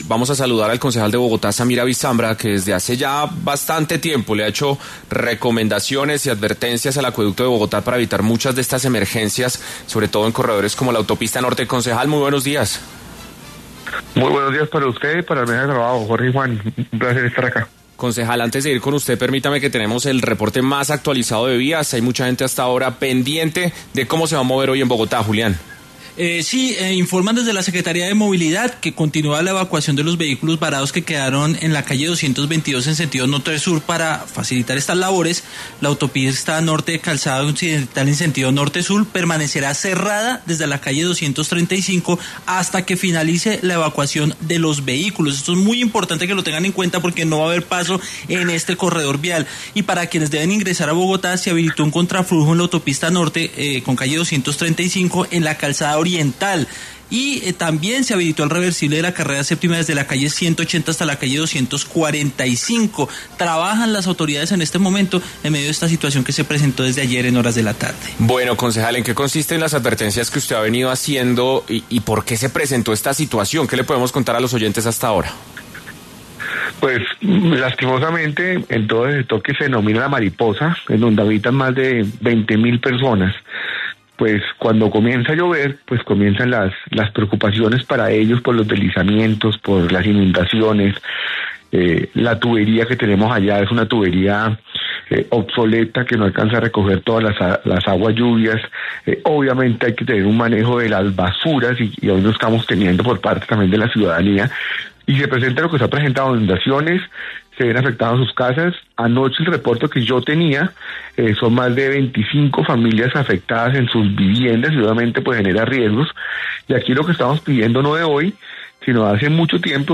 El secretario de Seguridad Bogotá, César Restrepo recomendó en 6AM a los ciudadanos tomar rutas alternas porque las inundaciones continúan, mientras que el concejal Samir Abisambra alertó por nuevas emergencias.